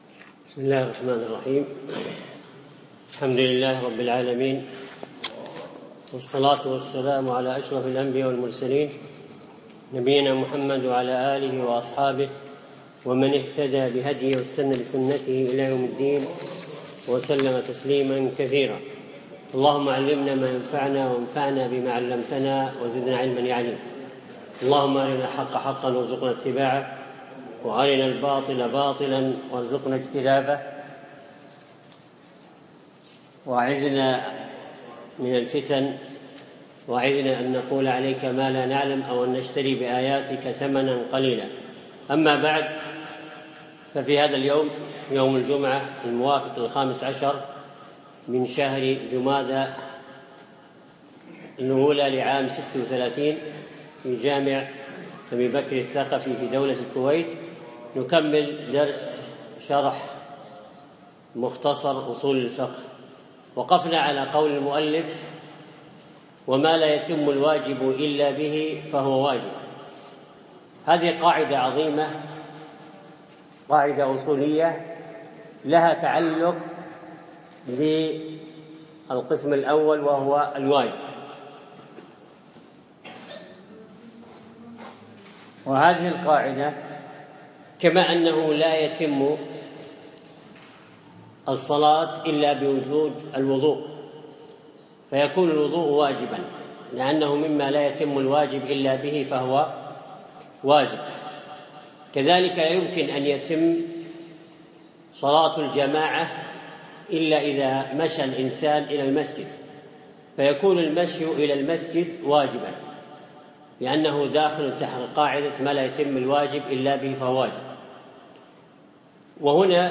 أقيم الدرس بعد عصر الجمعة 6 3 2015 في مسجد أبي بكرة الثقفي منطقة العارضية
الدرس الثاني